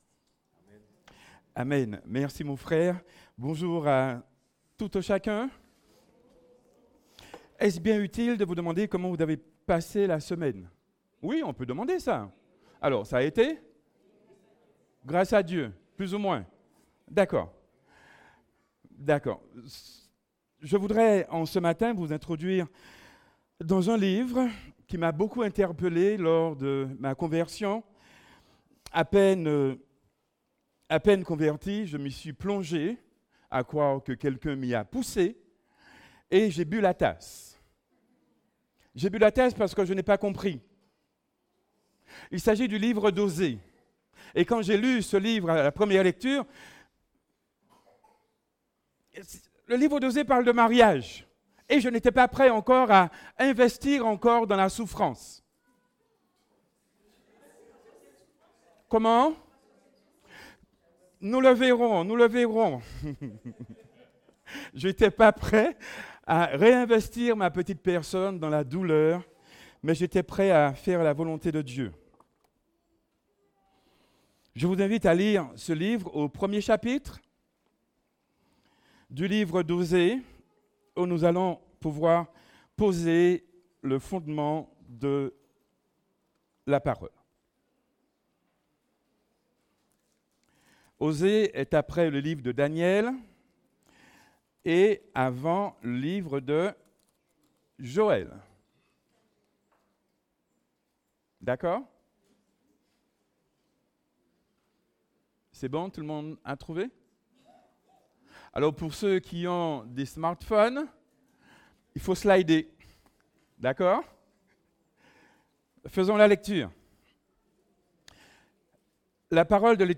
je t’aime d’un amour éternel Prédicateur